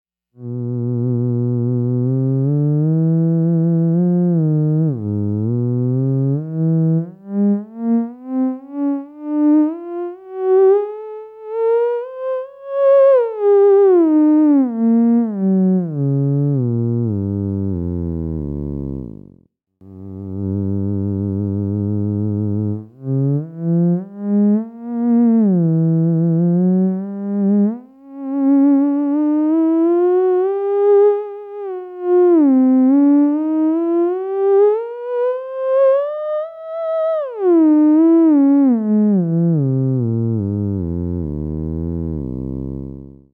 This is Raw direct to sound card, no reverb or effects
A deep harmonically rich low end, did someone say Cello?
phoenix_cello2.mp3